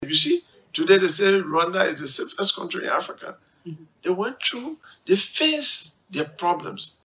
In an interview with  OK FM on November 19 Cllr. Tawon Gonglo claimed that Rwanda is the safest country in Africa.